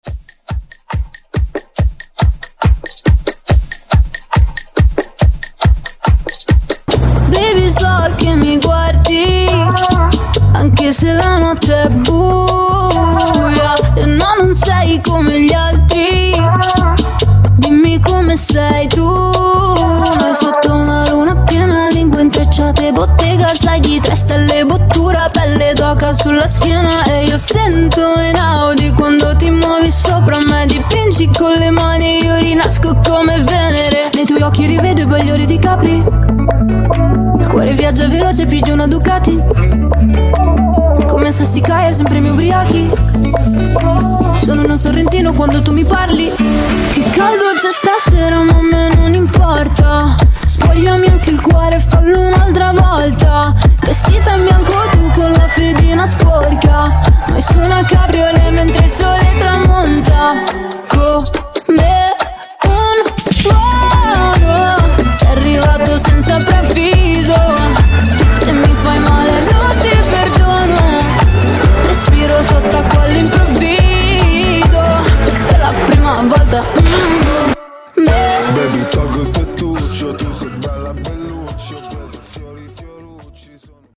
Genres: RE-DRUM , TOP40 Version: Clean BPM: 140 Time